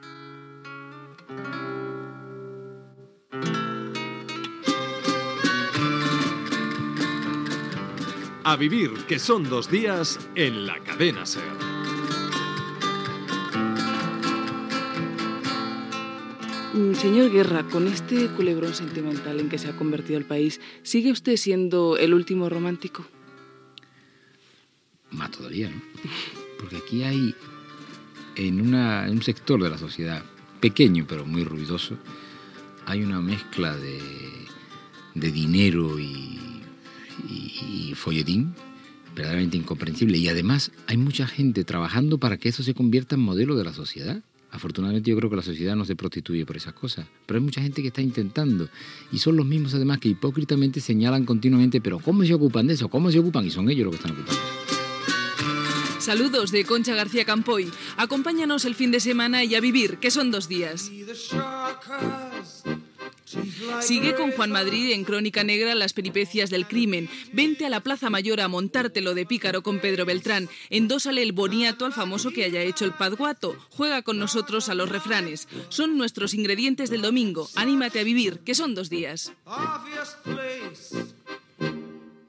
Careta del programa, pregunta al vicepresident del govern espanyol Alfonso Guerra, sumari de continguts
Entreteniment